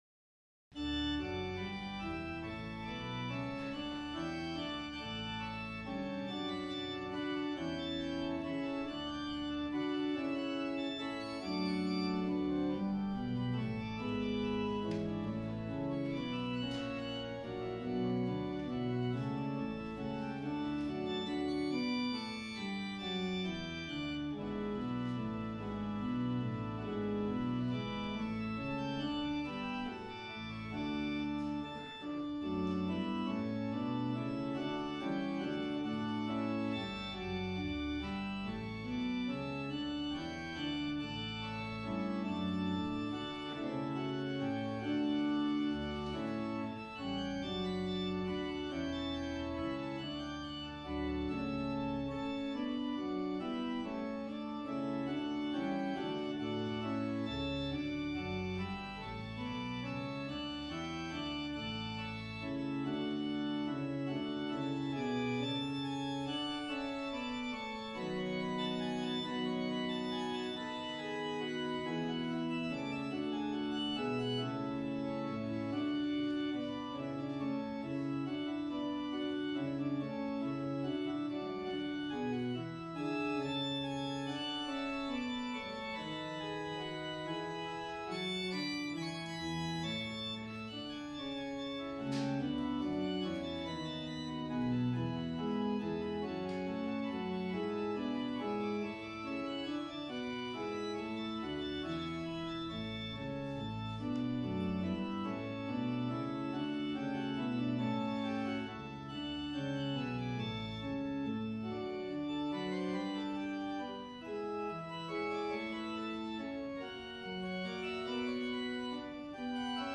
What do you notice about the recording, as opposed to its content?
Peace Evangelical Lutheran Church - Christmas Eve Service 2020